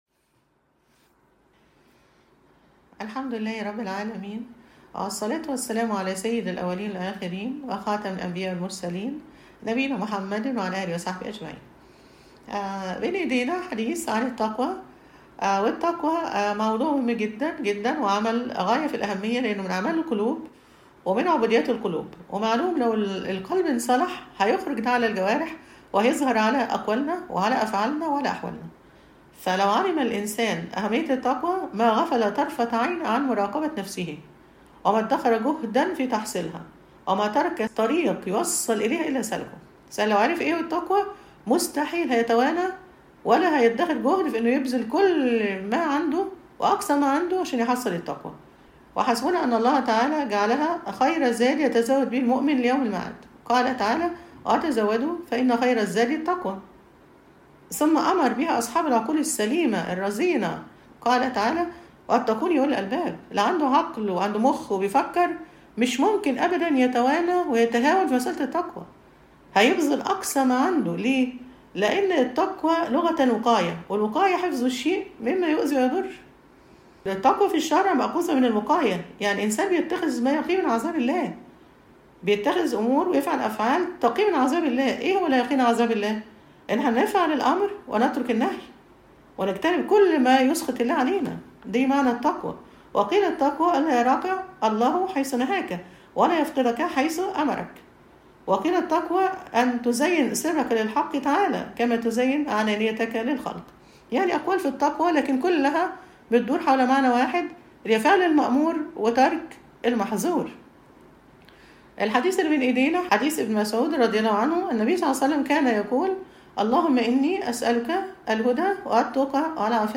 المحاضرة السابعة_”التقوى”